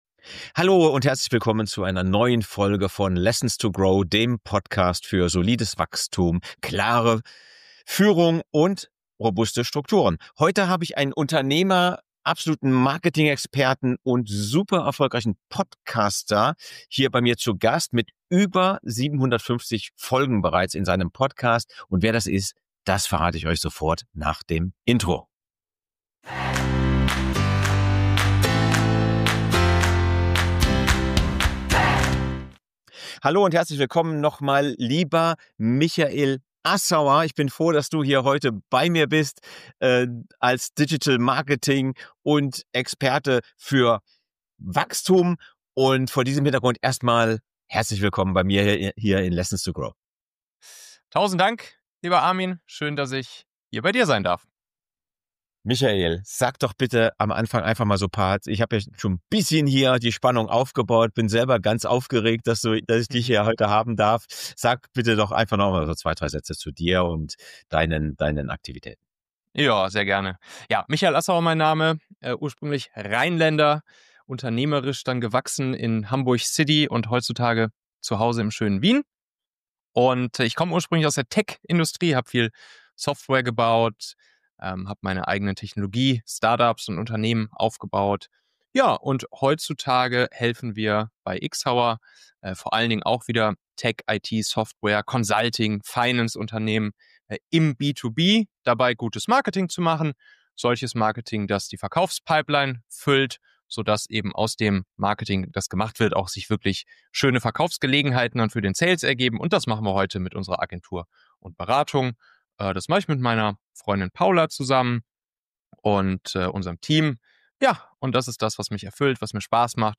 Nr. 57 - Performance Content Marketing zur Leadgenerierung. Interview